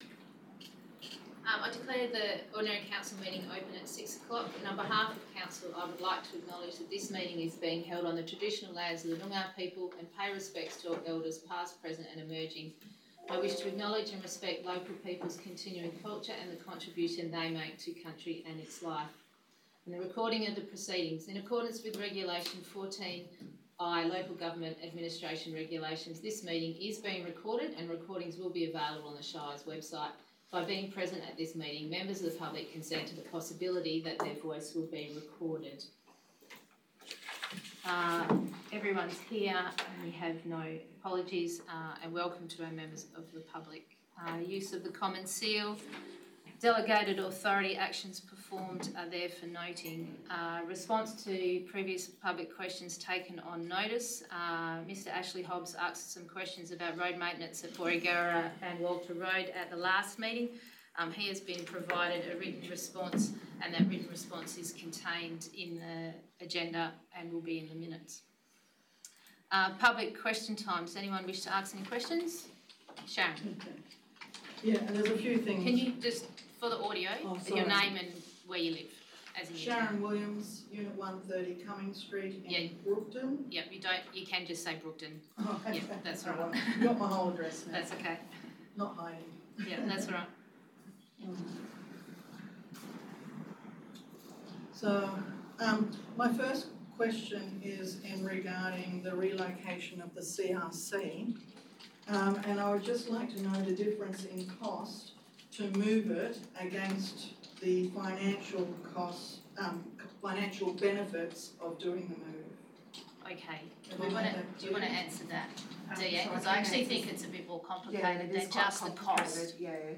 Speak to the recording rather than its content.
18 September 2025 - Ordinary Meeting of Council » Shire of Brookton